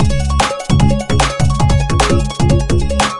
描述：trippy circus or carnival sound fx
声道立体声